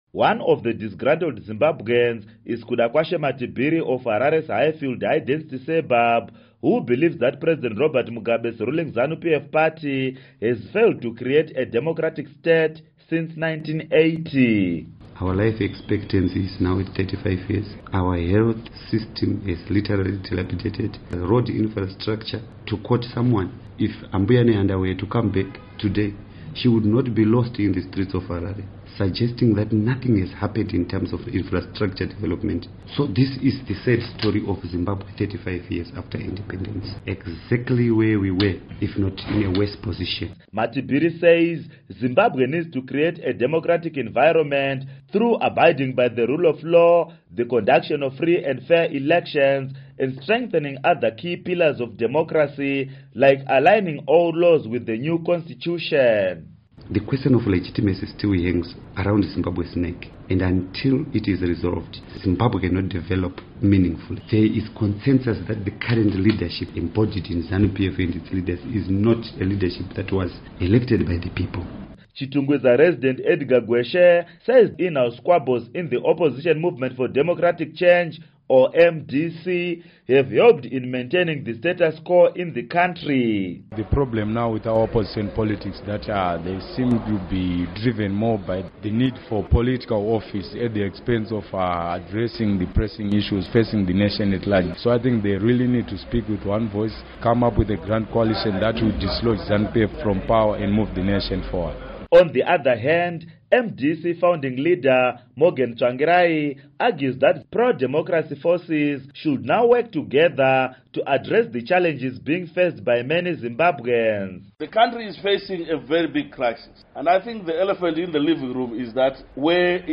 Independence Report on Zimbabwe Politics